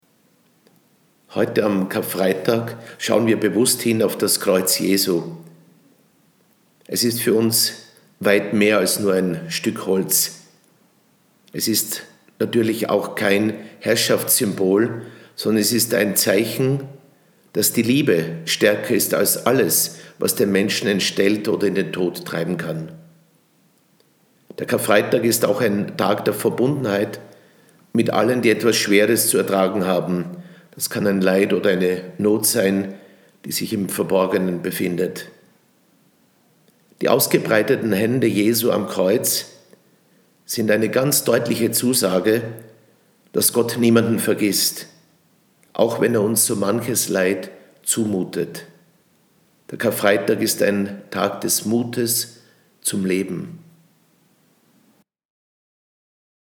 Audiobotschaft von Bischof Glettler zum Karfreitag